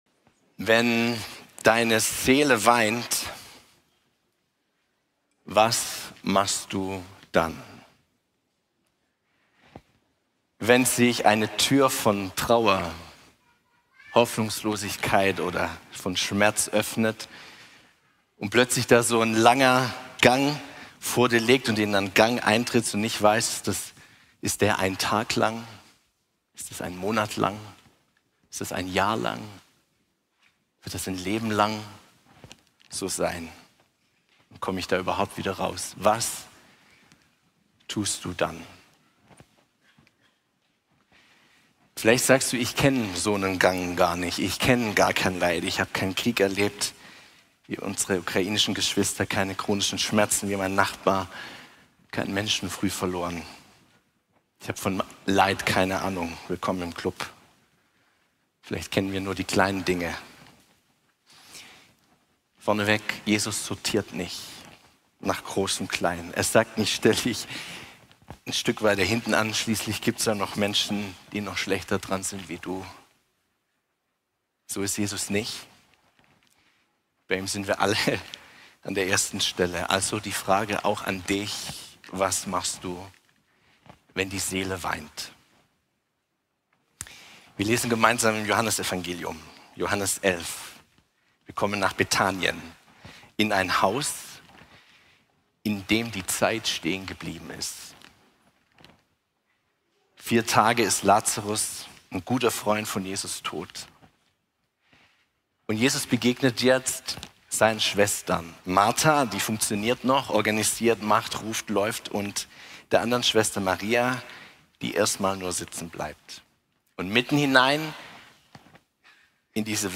Typ: Predigt